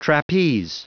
Prononciation du mot trapeze en anglais (fichier audio)
Prononciation du mot : trapeze